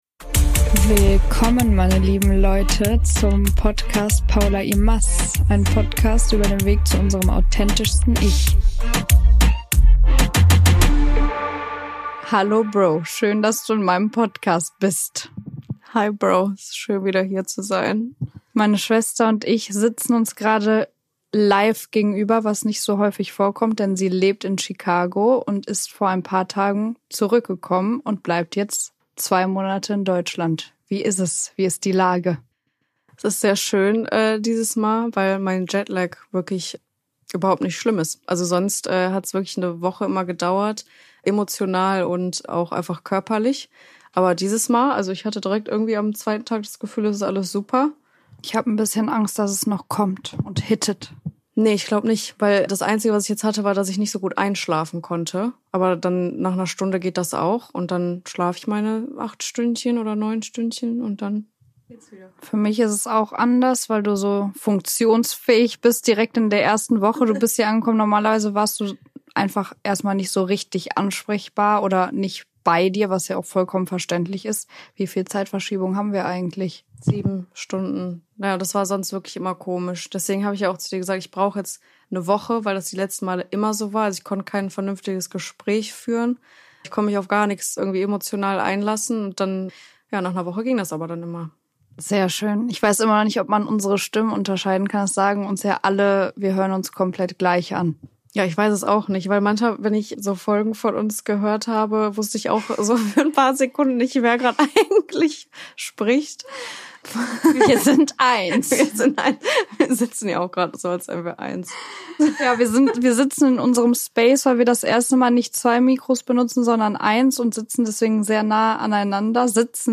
Kleiner Spoiler: Am Ende gibt es, wie immer, einen kleinen Lacher von uns.